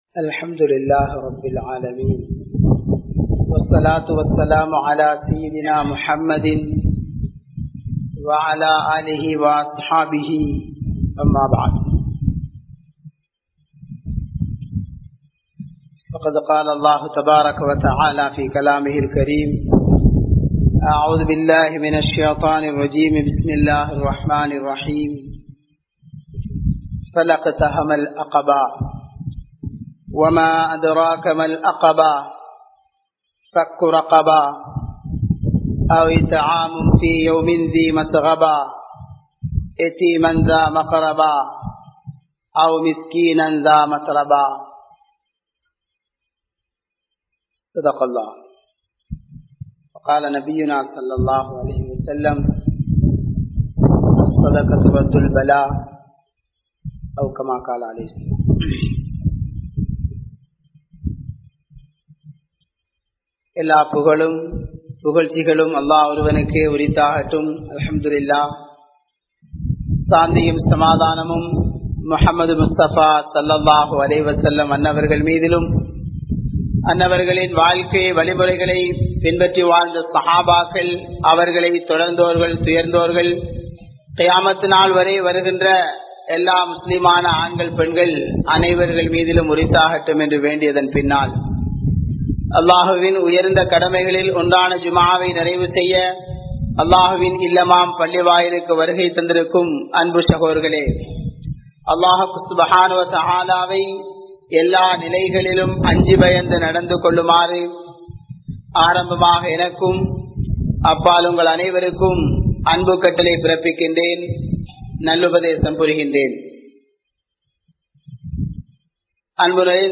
Sathaqa vin Sirappu (ஸதகாவின் சிறப்பு) | Audio Bayans | All Ceylon Muslim Youth Community | Addalaichenai